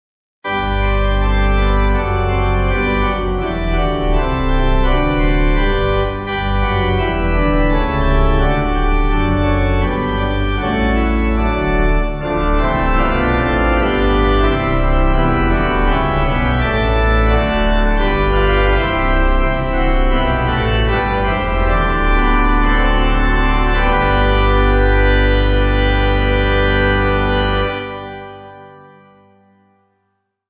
8.8.8.8 with Refrain
Organ